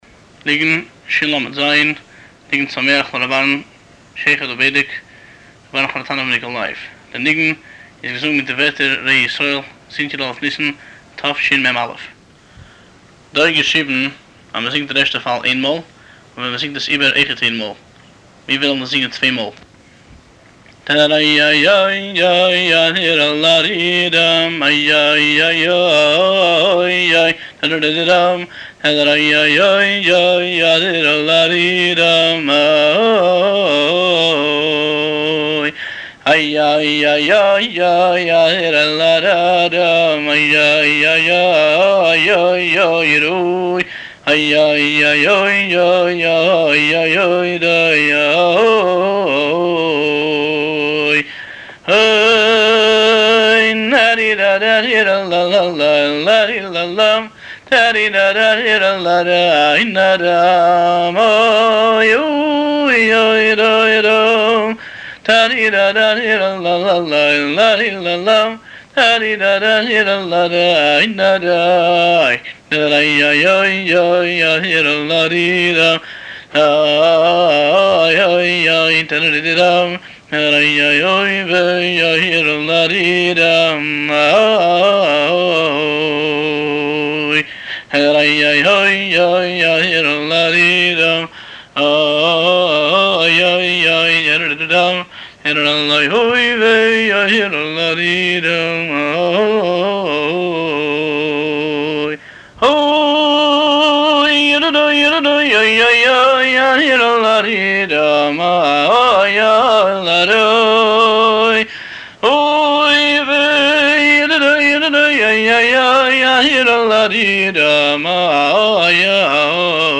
ניגון זה הוא ניגון שמחה לר' אהרן חריטונוב מניקולייב והושר בהתוועדויות הרבי.